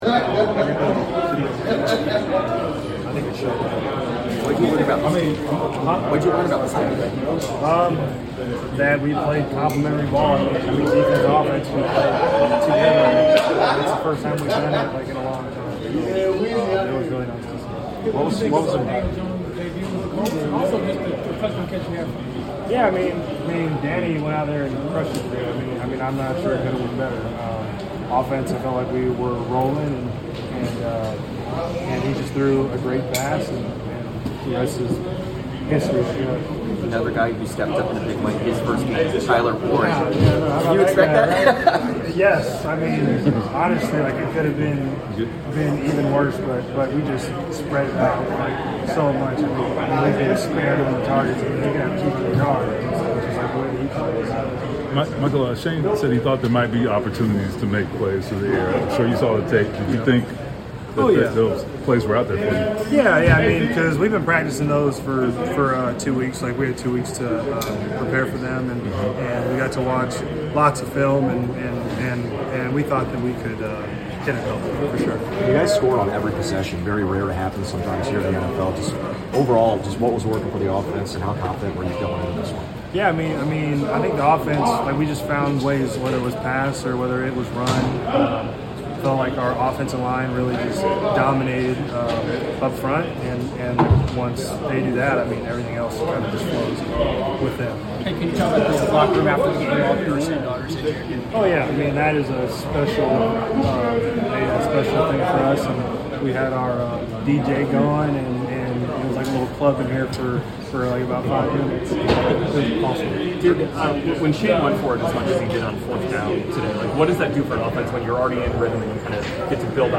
Indianapolis Colts Wide Receiver Michael Pittman Jr. Postgame Interview after defeating the Miami Dolphins at Lucas Oil Stadium.